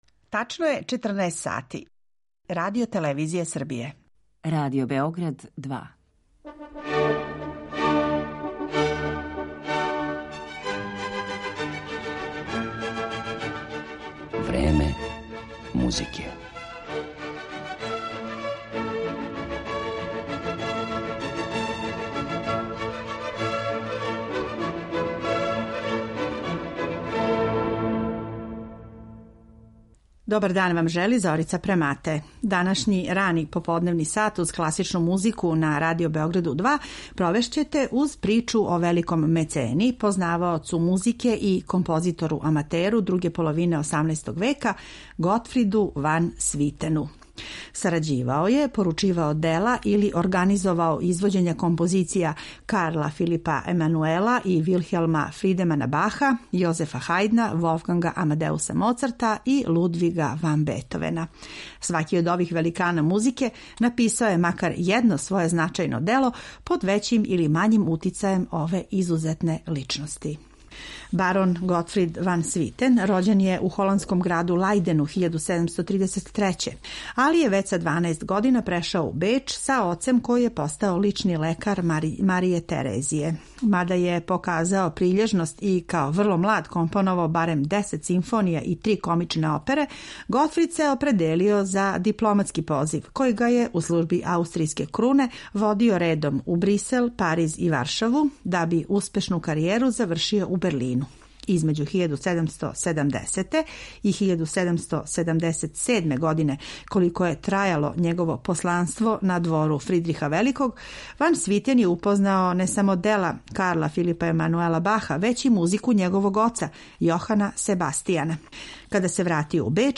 Сарађивао је, поручивао дела или организовао извођења композиција Карла Филипа Емануела и Вилхелма Фридемана Баха, Јозефа Хајдна, Волфганга Амадеуса Моцарта и Лудвига ван Бетовена. Сваки од ових великана написао је макар једно своје значајно дело под већим или мањим утицајем ове изузетне личности, а у емисији ћете имати прилику и да чујете најпознатија од њих.